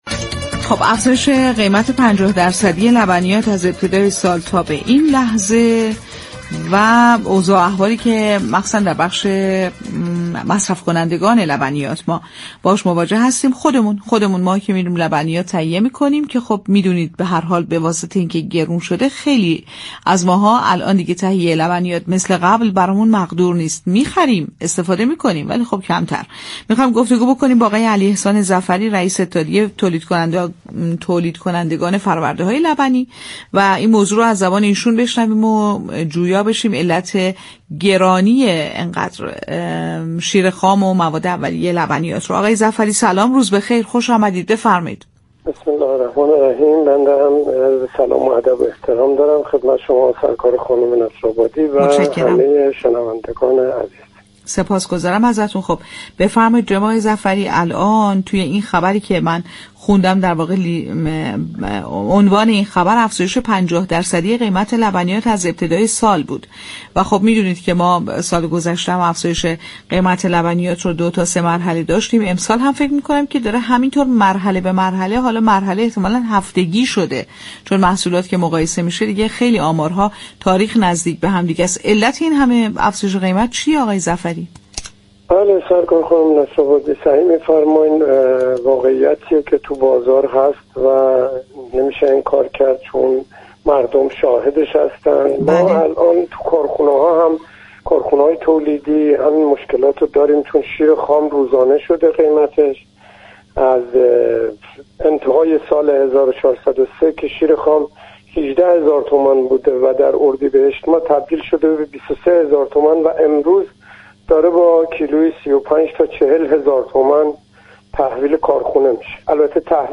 مصاحبه با رادیو تهران